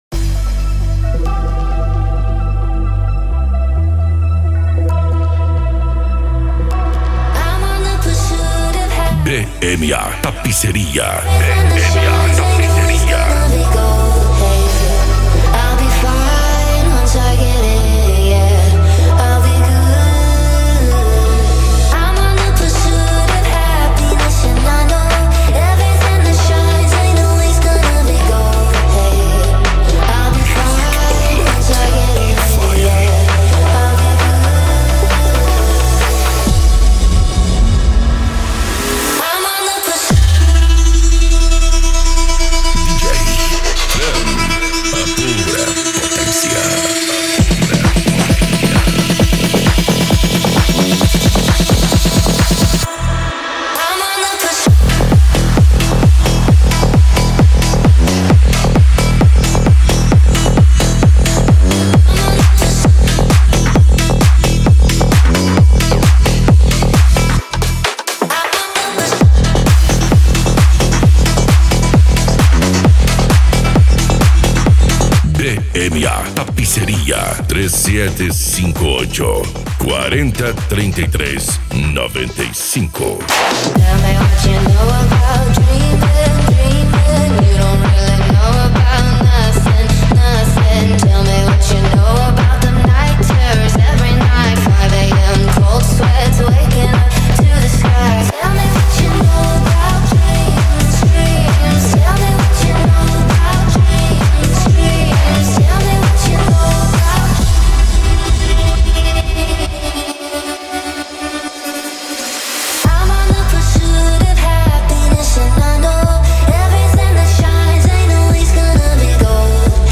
Eletronica
Musica Electronica
Psy Trance